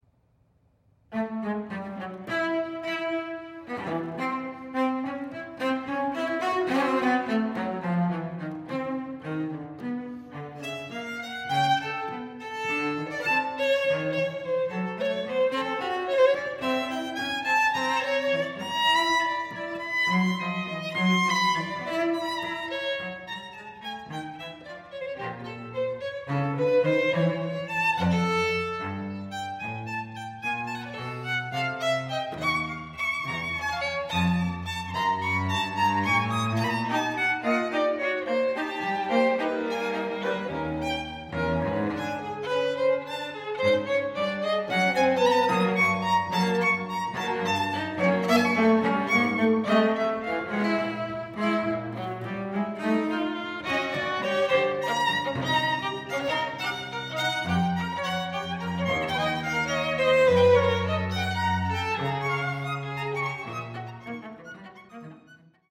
Longtime Duke University string quartet in residence